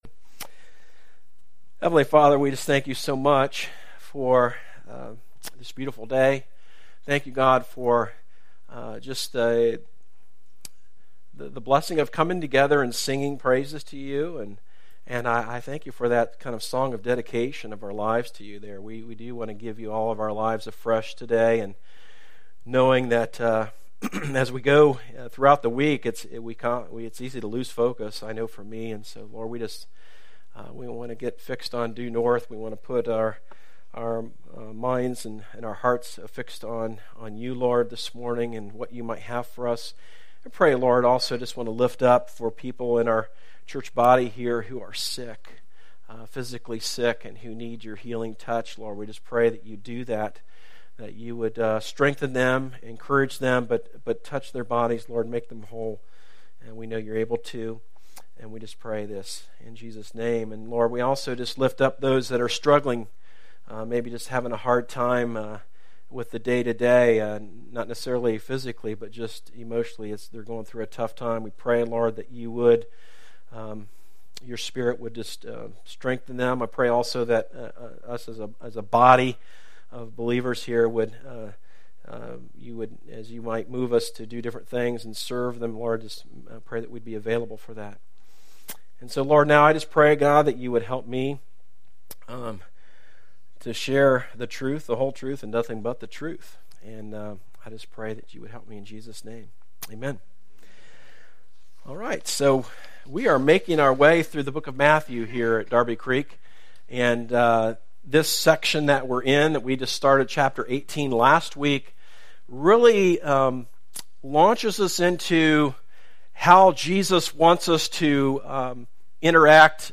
A message from the series "King And Kingdom."